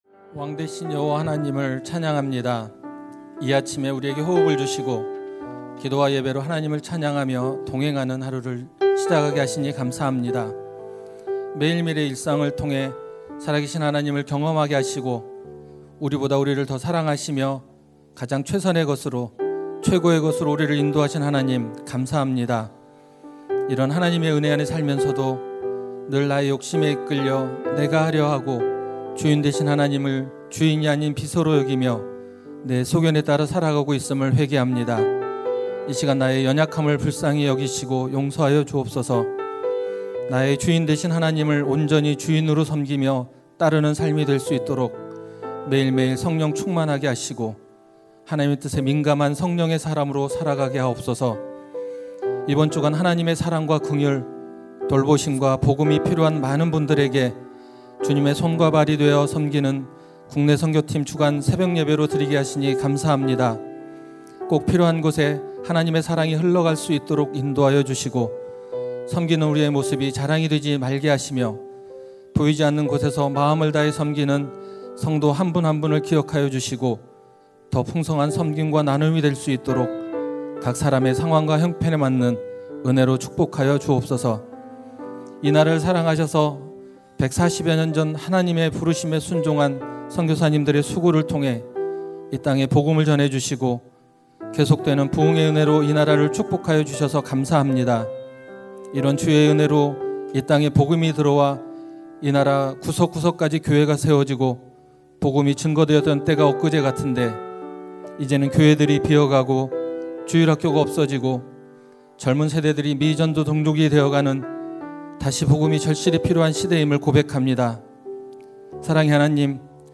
2025-05-30 사역팀주관새벽기도회
> 설교
[새벽예배]